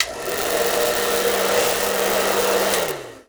Blow Dryer 03
Blow Dryer 03.wav